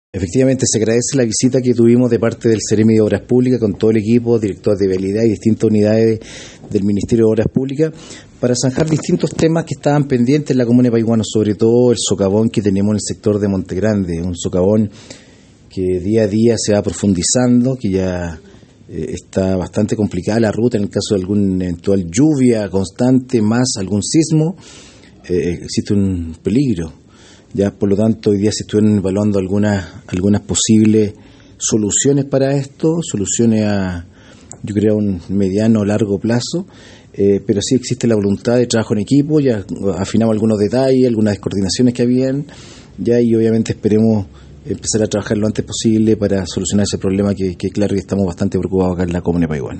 Respecto a la reunión y los acuerdos, el alcalde de la comuna de Paihuano, Hernán Ahumada, indicó que